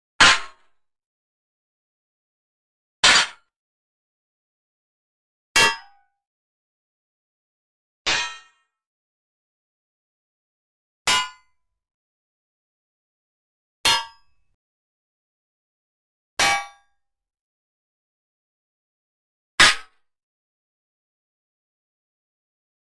Armor Hits | Sneak On The Lot